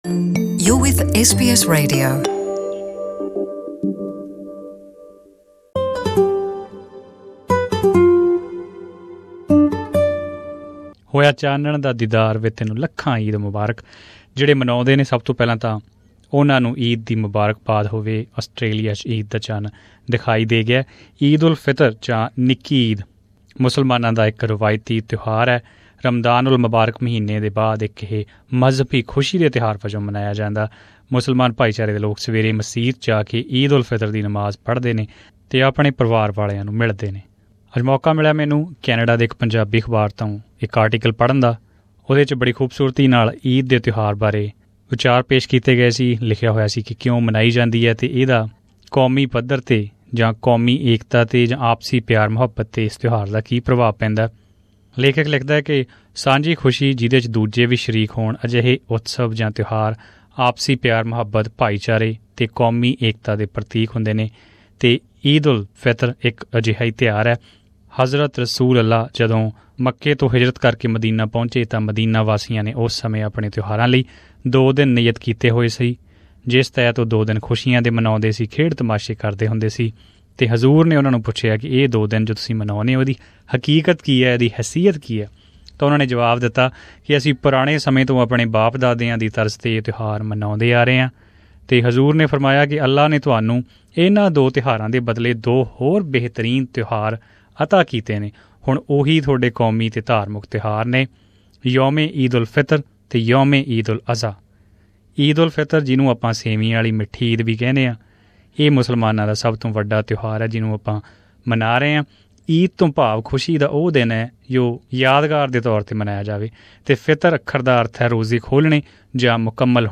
Listen to this audio report for more information…